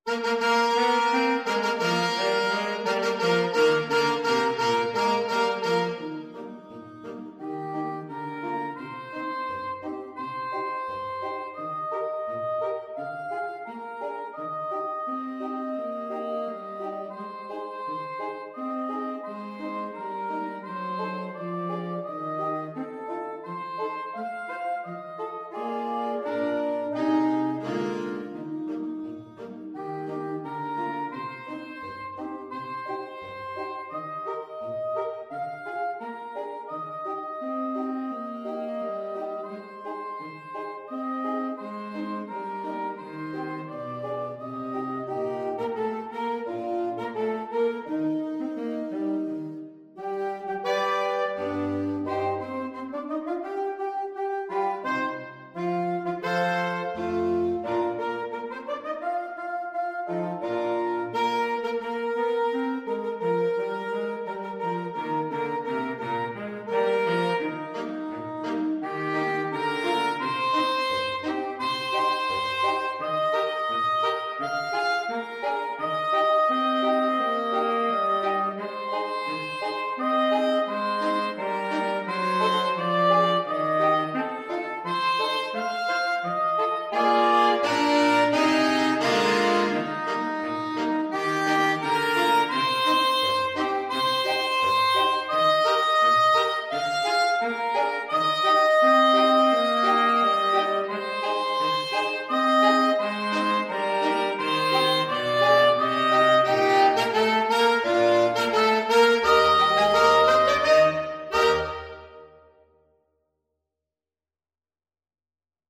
2/2 (View more 2/2 Music)
March Tempo - Moderato = c.86